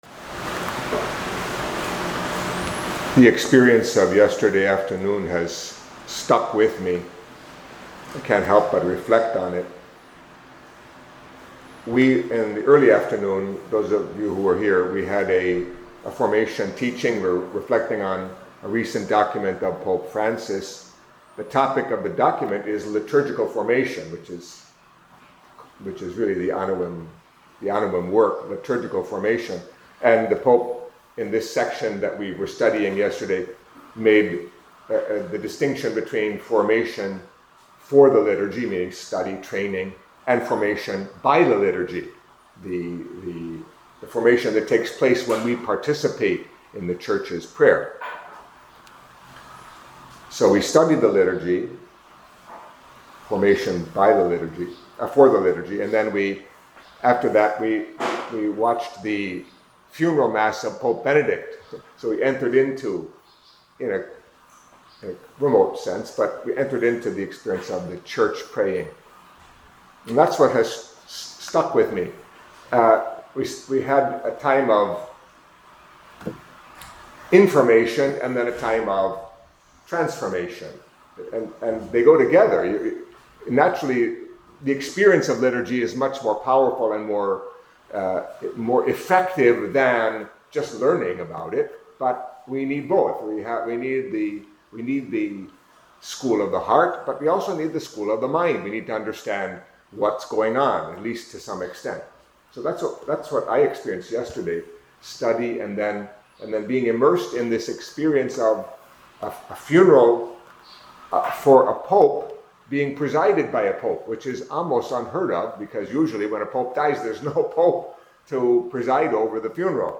Catholic Mass homily for Friday Before Epiphany